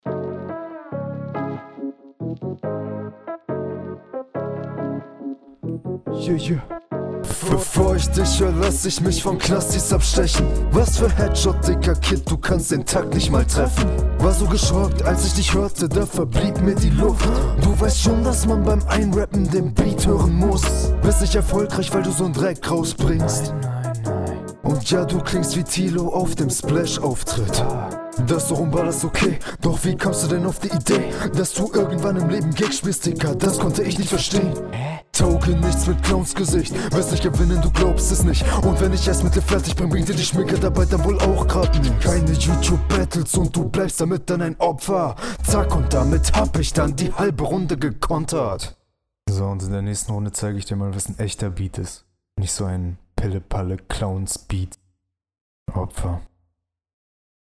Stabile Konter und Onbeat, outro fand ich akllerdings unnötig.